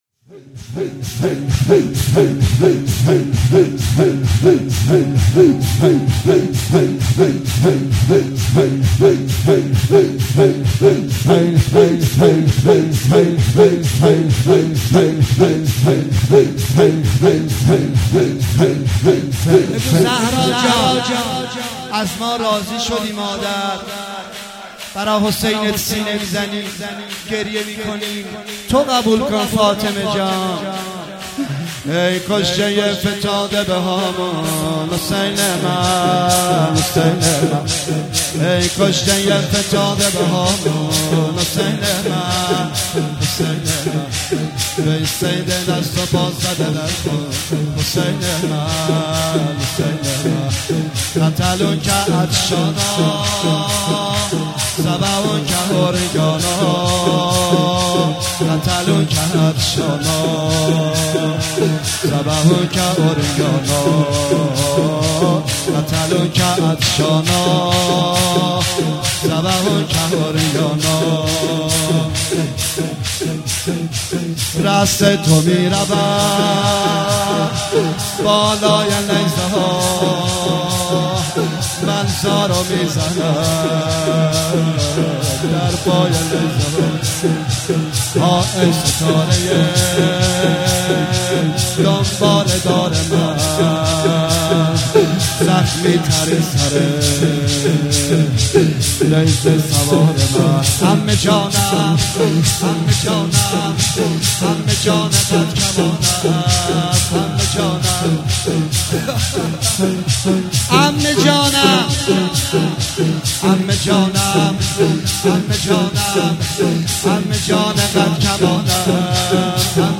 مناسبت : شب سوم محرم
قالب : شور